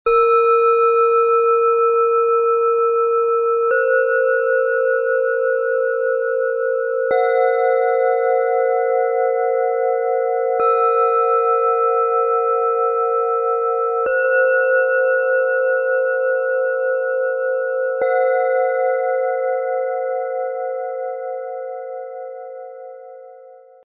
Ruhe, Heiterkeit & Aufstieg - Ein Klangset - Set aus 3 Klangschalen, Ø 10,8 - 12,8 cm, 1,33 kg
• Klangmeditation & Achtsamkeit – sanfte, helle Töne für tiefe Entspannung
Ihr stabiler Ton schenkt Ruhe, Gelassenheit und ein Gefühl der Geborgenheit. Die sanfte, aber kraftvolle Schwingung zieht die Energie nach unten, erdet und verankert. Sie begleitet uns wie eine sichere Hand auf dem Weg zur inneren Balance.
Ihr freundlicher Klang verbreitet Leichtigkeit und Ausgeglichenheit. Sie öffnet den Raum mit sanfter Schwingung und lädt dazu ein, aufzuatmen und das Leben mit einem Lächeln zu betrachten. Ihre harmonische Frequenz schafft eine Atmosphäre von Wärme und Geborgenheit.
Ihr heller, lebendiger Ton holt uns ab und führt uns nach oben. Mit spielerischer Leichtigkeit springt ihre Schwingung in den Raum – fast so, als würde sie uns sanft in die Ewigkeit tragen. Diese Klangschale bringt Klarheit, Frische und geistige Weite.
Im Sound-Player - Jetzt reinhören können Sie den Original-Ton genau dieser Schalen anhören und sich von ihrer einzigartigen Schwingung inspirieren lassen.
Bengalen Schale, Schwarz-Gold, 12,8 cm Durchmesser, 6,8 cm Höhe
MaterialBronze